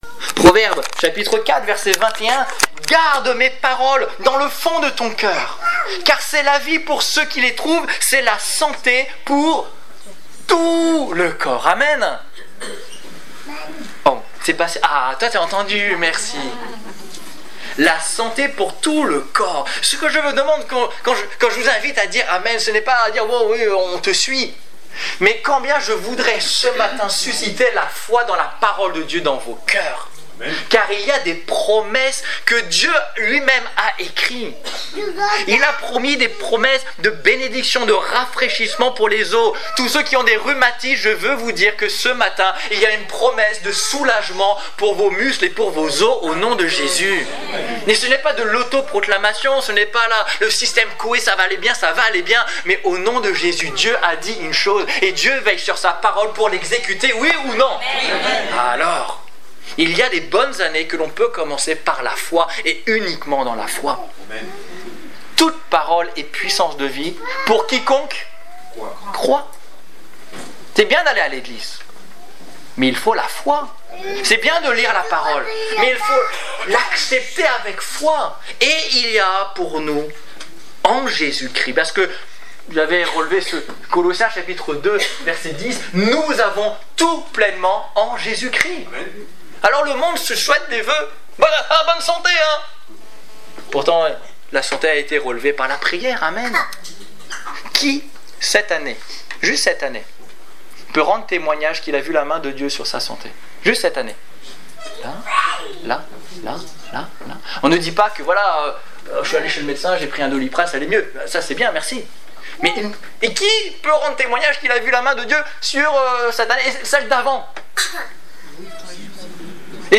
Exhortation - Culte du 10 janvier 2016